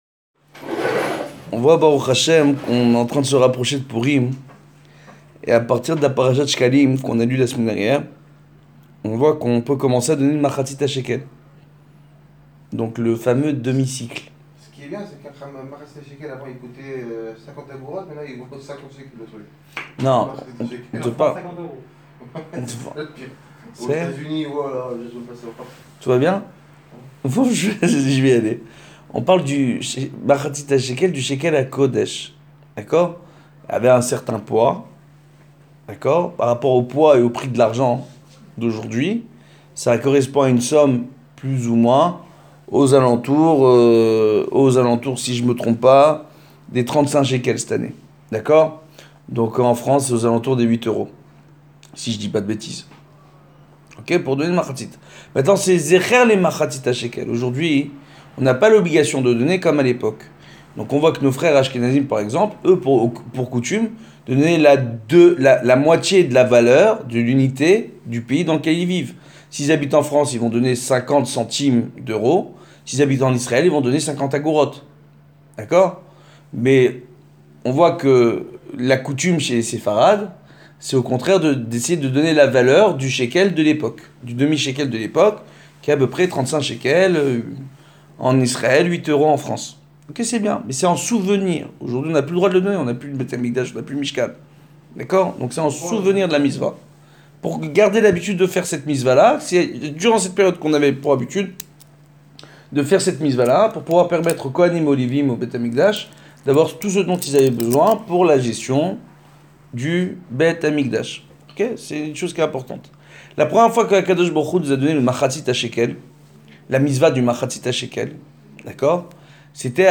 Nouveau cours audio : « Le secret du mahatsite hashekel et des chérubins«